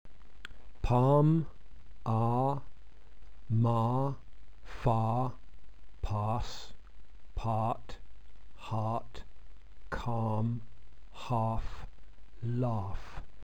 English tense vowels